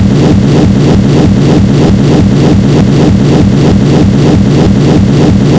waves.mp3